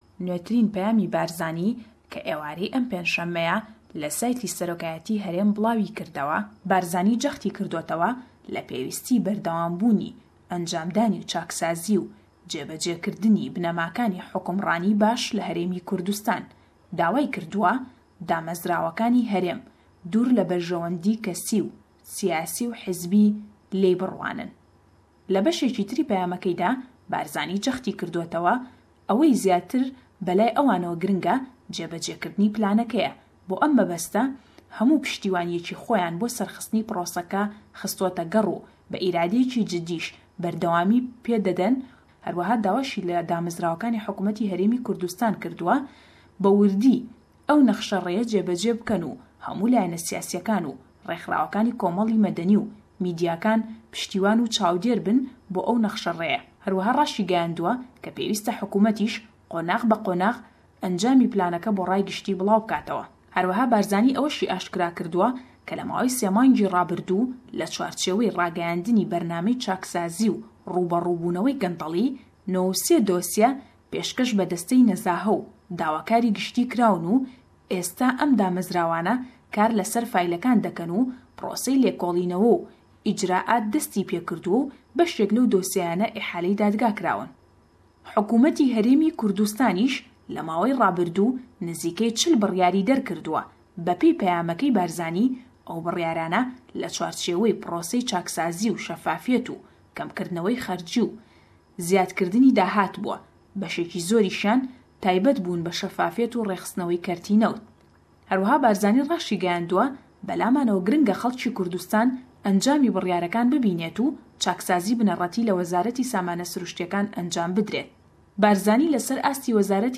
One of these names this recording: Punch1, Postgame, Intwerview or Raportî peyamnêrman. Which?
Raportî peyamnêrman